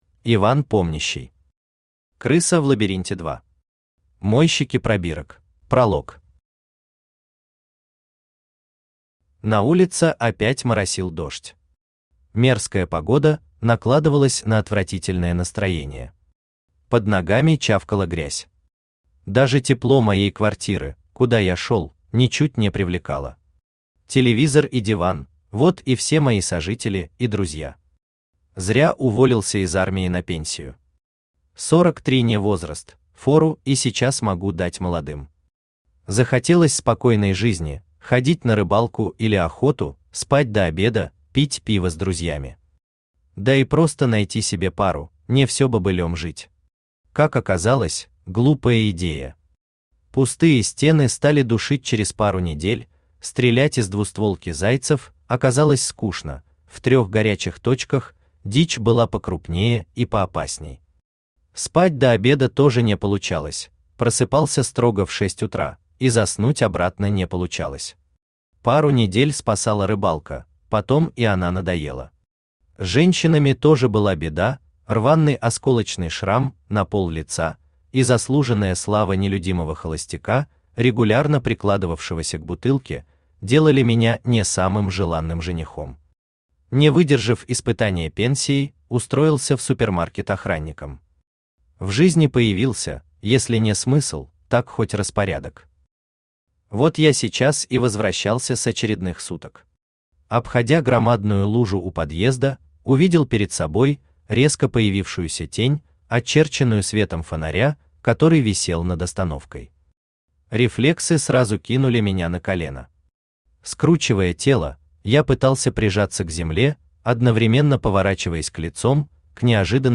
Аудиокнига Крыса в лабиринте 2. Мойщики пробирок | Библиотека аудиокниг
Мойщики пробирок Автор Иван Помнящий Читает аудиокнигу Авточтец ЛитРес.